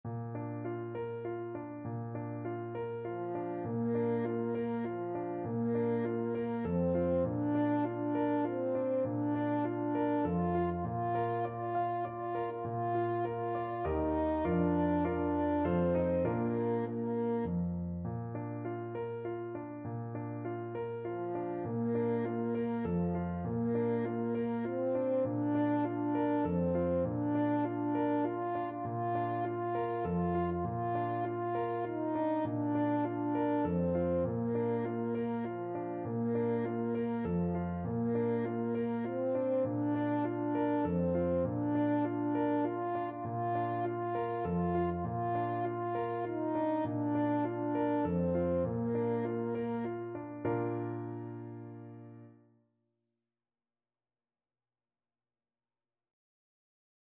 French Horn
Bb major (Sounding Pitch) F major (French Horn in F) (View more Bb major Music for French Horn )
3/4 (View more 3/4 Music)
Gently =c.100
Traditional (View more Traditional French Horn Music)